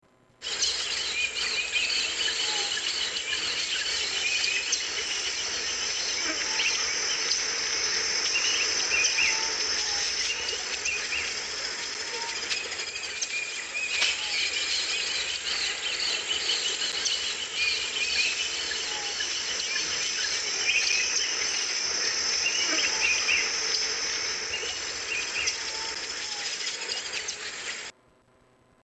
Rainforest Ambience 8
Category: Animals/Nature   Right: Personal